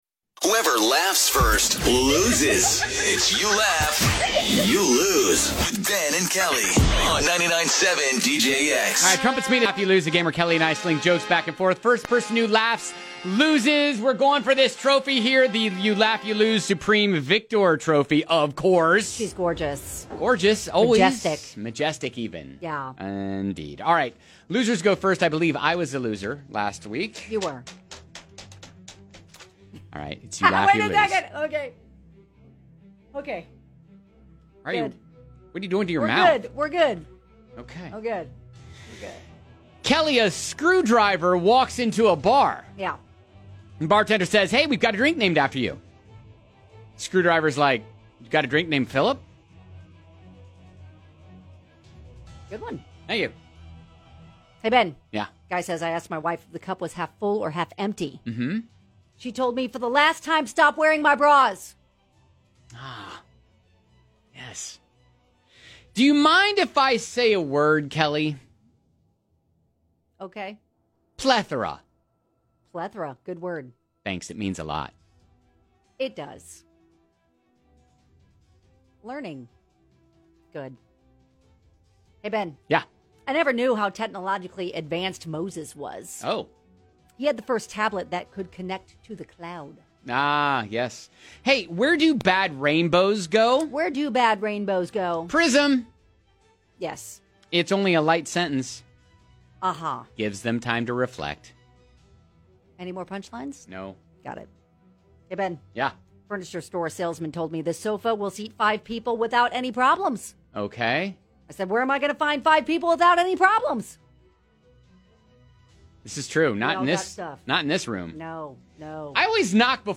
toss jokes back and forth until someone laughs